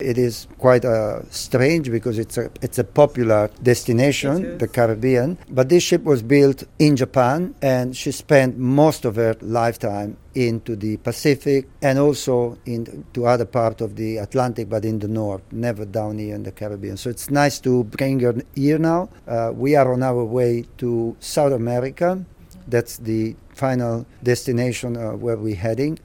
Ministers of Government along with other tourism officials and cruise company representatives attended a Plaque Exchanging Ceremony aboard the ship.